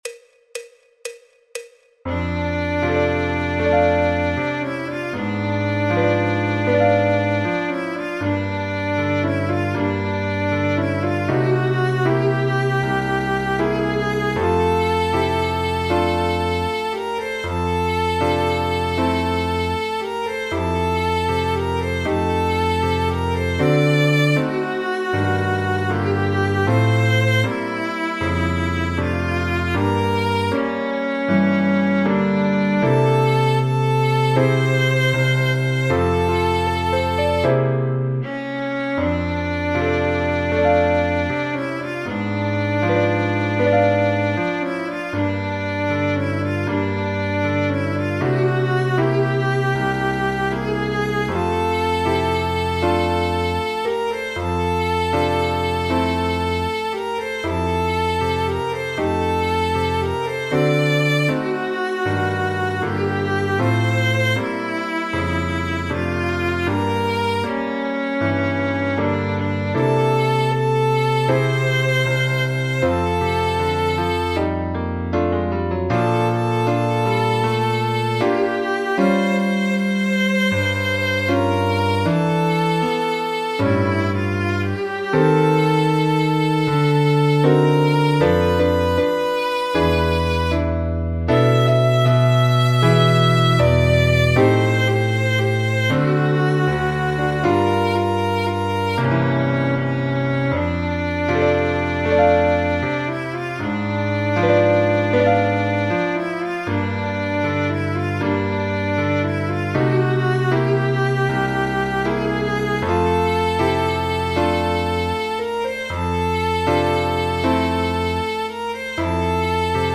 El MIDI tiene la base instrumental de acompañamiento.
Fa Mayor
Jazz, Popular/Tradicional